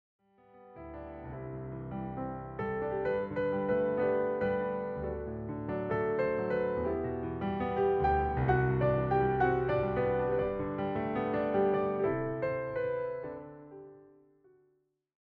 all given a solo piano treatment.